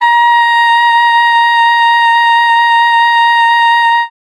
42c-sax12-a#5.wav